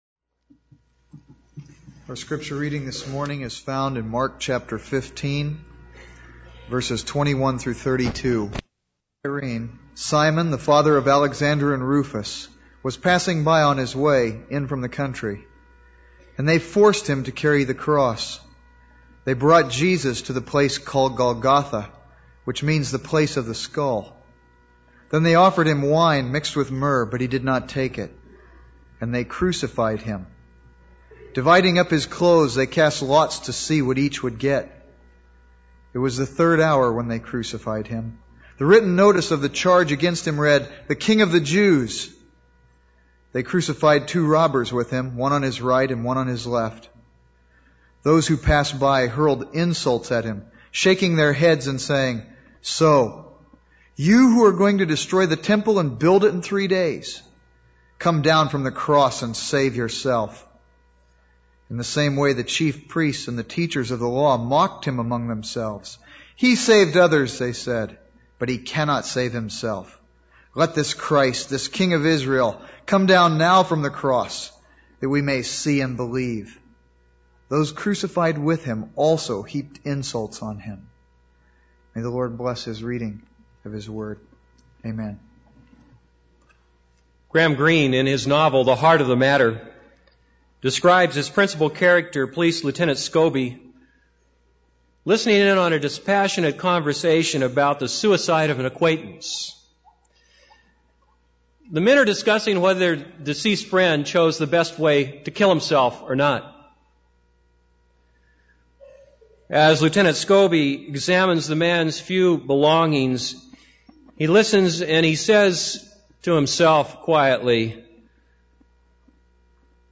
This is a sermon on Mark 15:21-32.